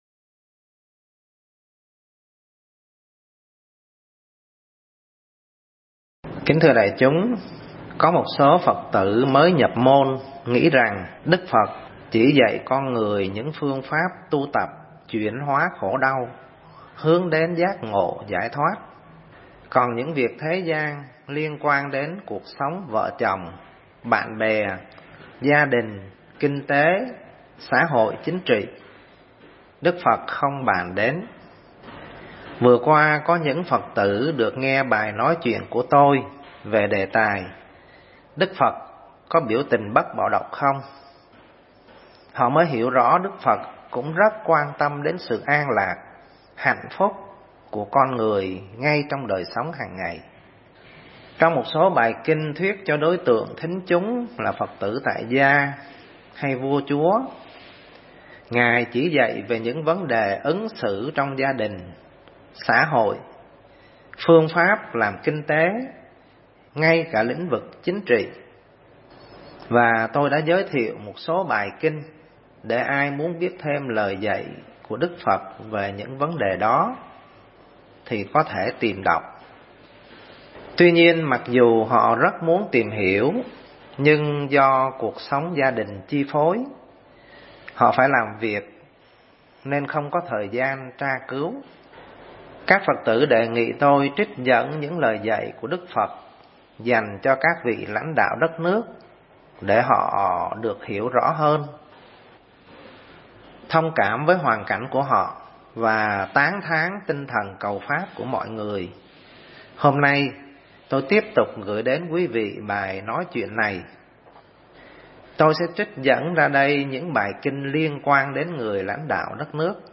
Mp3 Thuyết Giảng Phật Nói Gì Với Người Lãnh Đạo Đất Nước